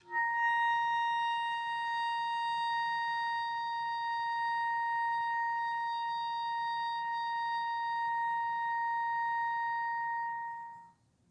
Clarinet
DCClar_susLong_A#4_v1_rr1_sum.wav